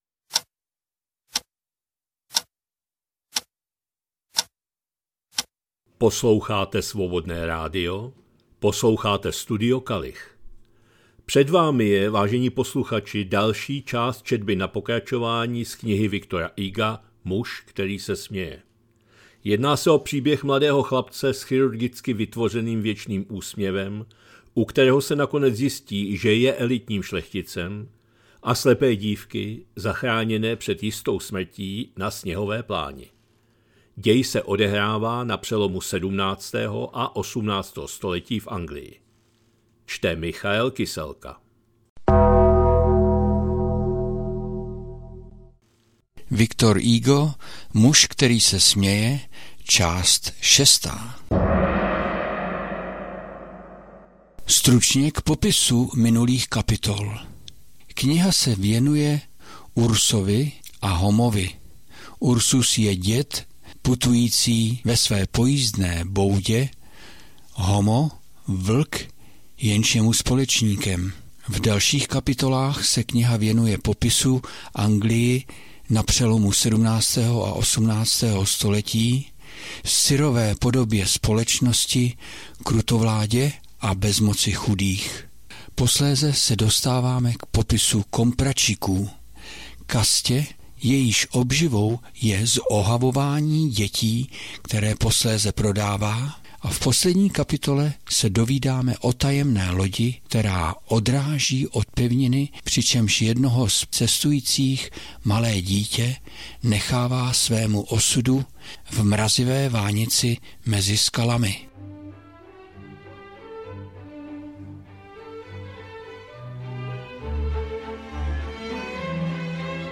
2025-03-26 – Studio Kalich – Muž který se směje, V. Hugo, část 6., četba na pokračování